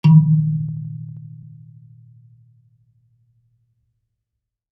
kalimba_bass-D#2-ff.wav